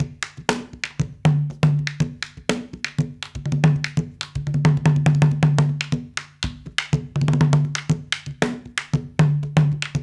120 -CONG0BR.wav